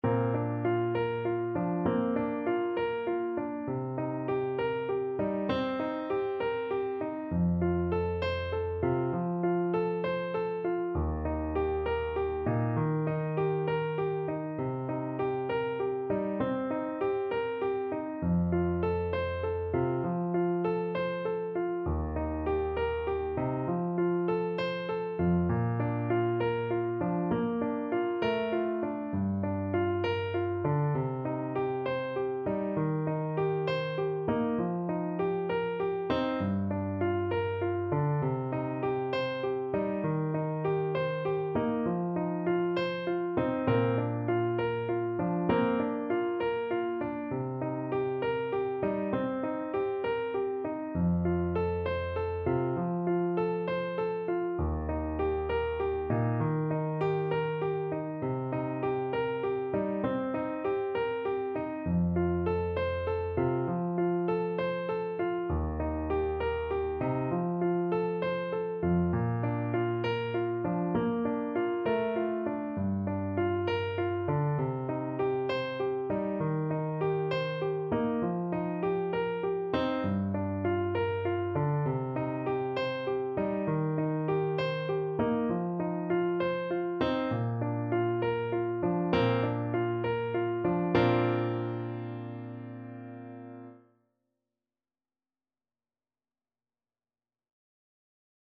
6/8 (View more 6/8 Music)